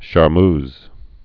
(shär-mz, -ms, -mœz)